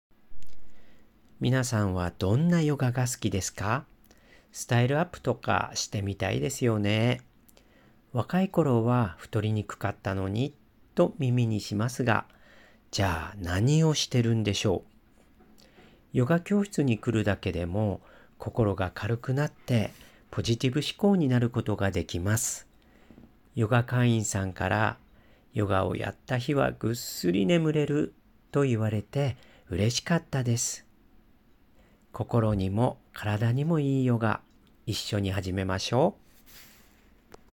このクラスは、毎週火曜10:15～第１スタジオで行われています